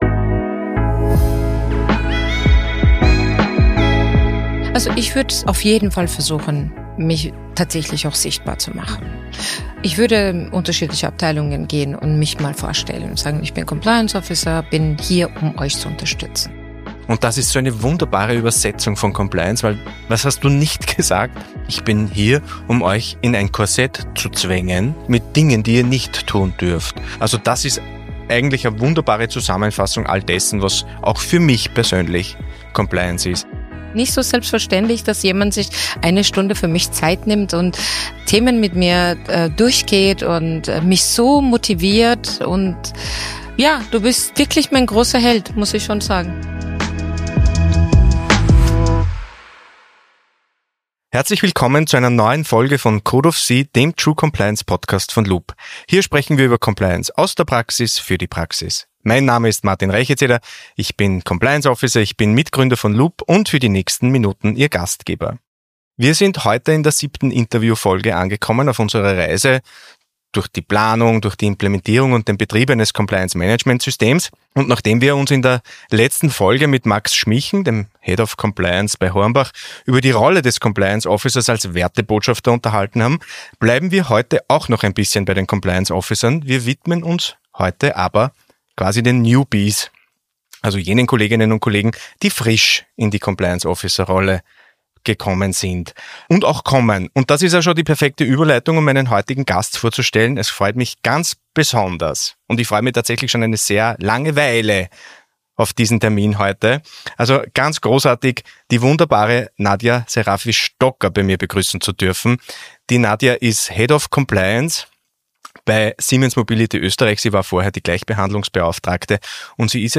In dieser Gesprächsfolge von Code of C geht es um den Einstieg in eine Schlüsselrolle und darum, wie man vom ersten Tag an Vertrauen schafft.